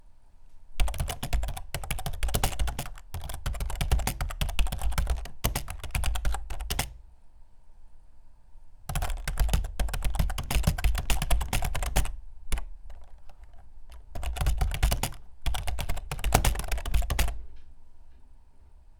computer_keyboard_fast
computer desktop keyboard typing writing sound effect free sound royalty free Memes